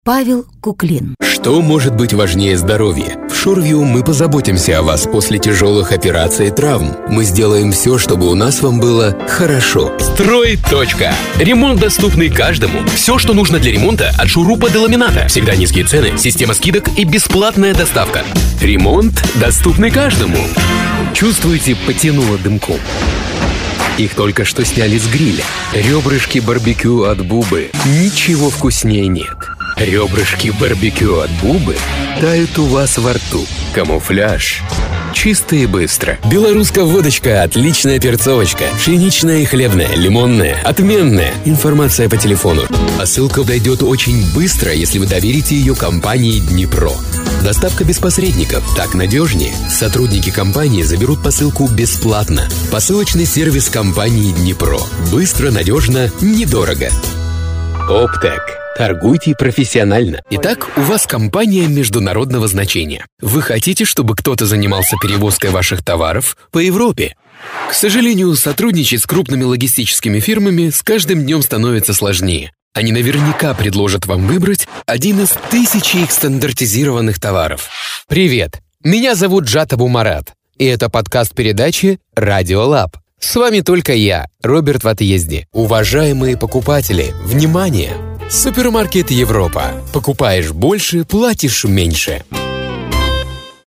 Тракт: Микрофоны: Rode NT2000, AT5040, Sennhiser 416, Sennhiser 441U, Shure SM7B .
Демо-запись №1 Скачать